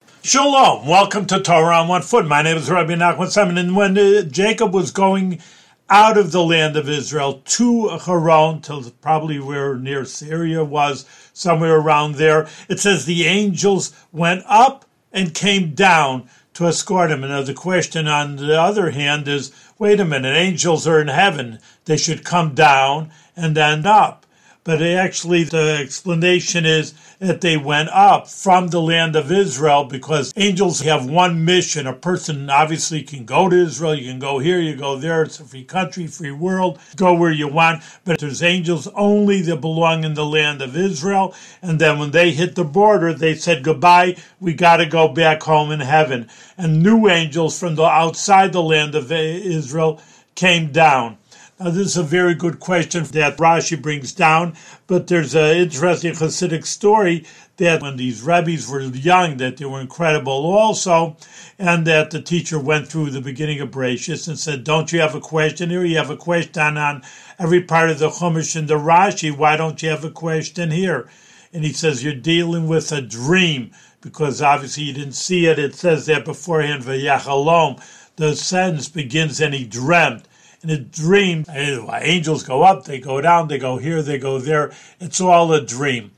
One-minute audio lessons on special points from weekly Torah readings in the Book of Genesis.